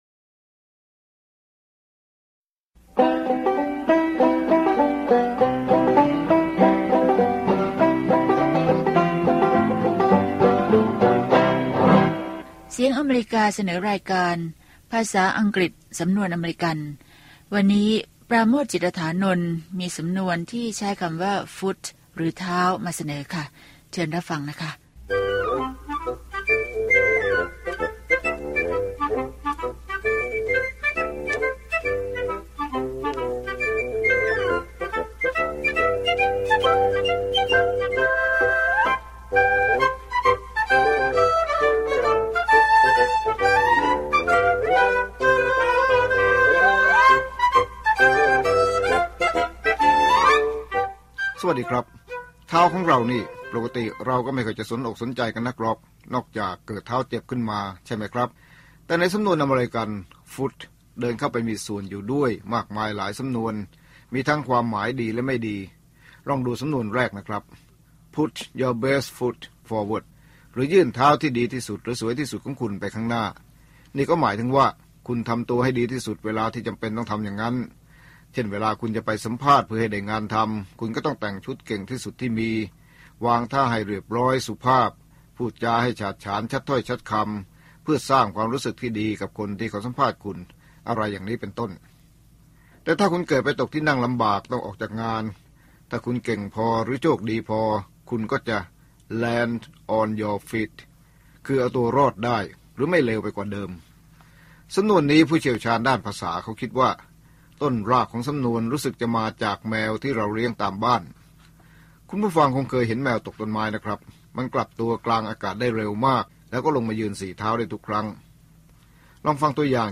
ภาษาอังกฤษสำนวนอเมริกัน สอนภาษาอังกฤษด้วยสำนวนที่คนอเมริกันใช้ มีตัวอย่างการใช้ และการออกเสียงจากผู้ใช้ภาษาโดยตรง